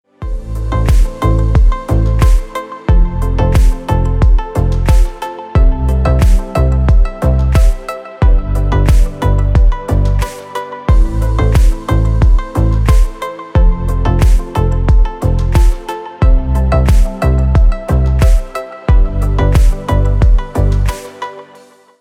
Спокойные рингтоны